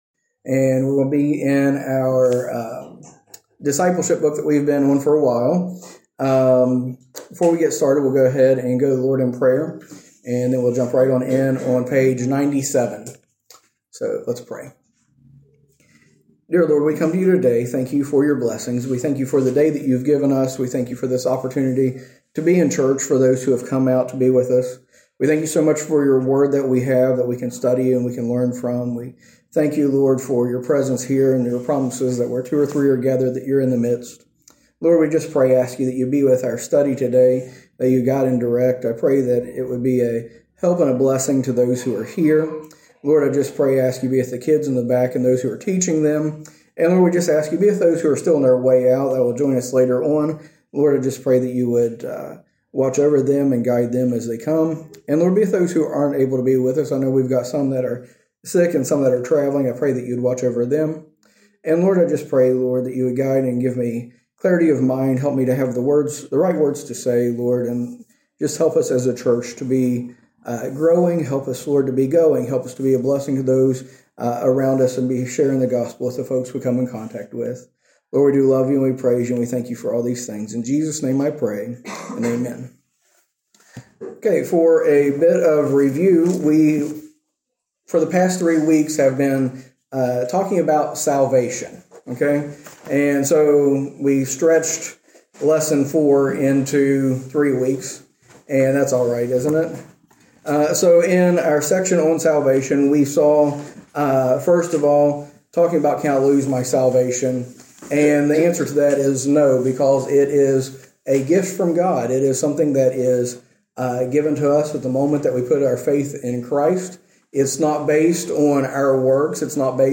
A message from the series "Continue."